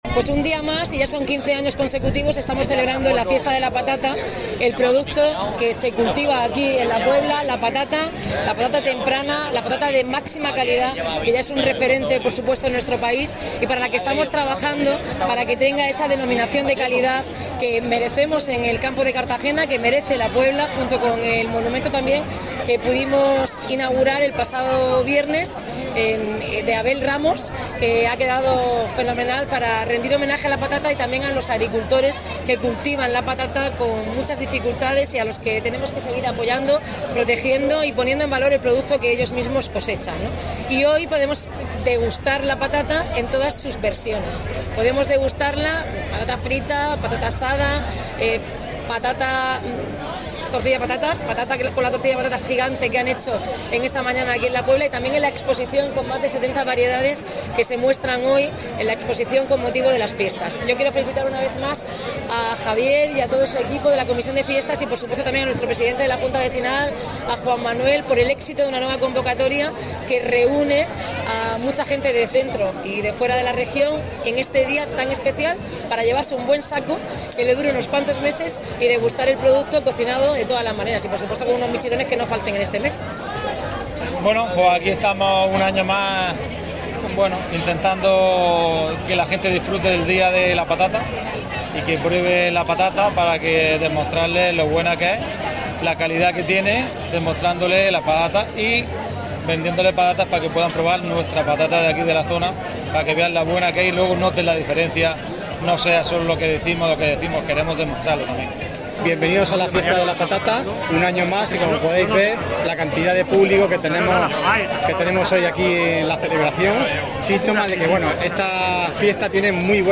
Miles de personas han disfrutado en La Puebla durante el pasado fin de semana de la Fiesta de la Patata.